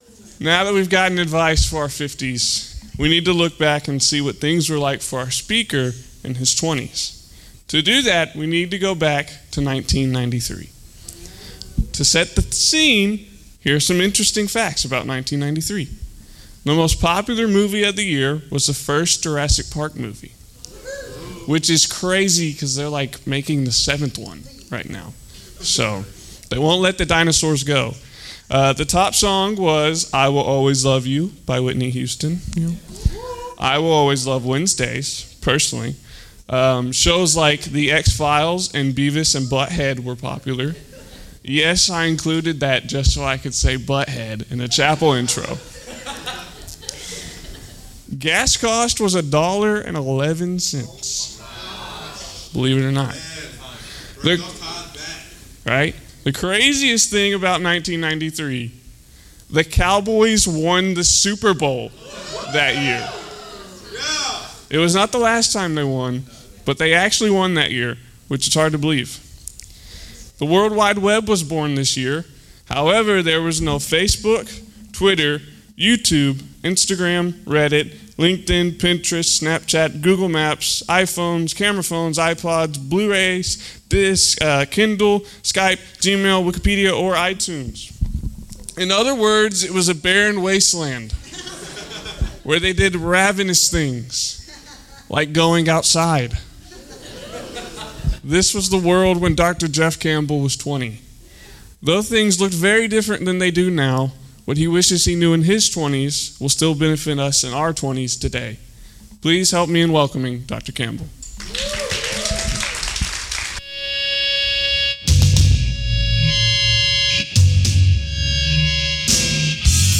Criswell College Wednesdays Chapel.